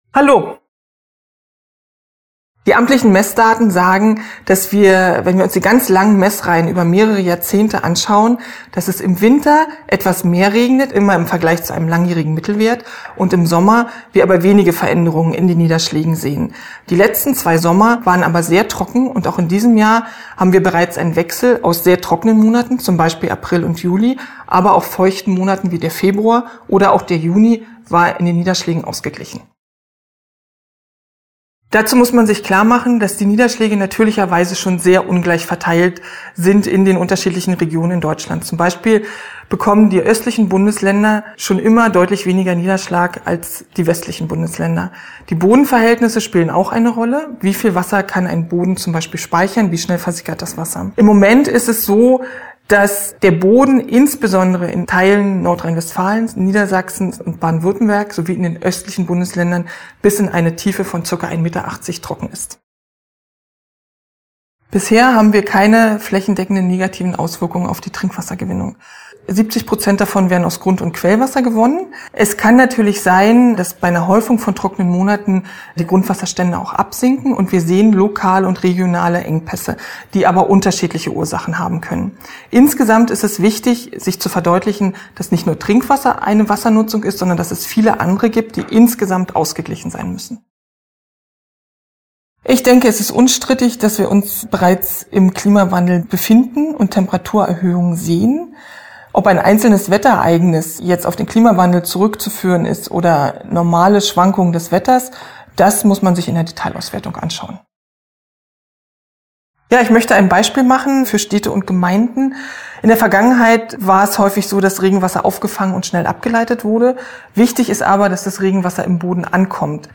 Interview: 2:37 Minuten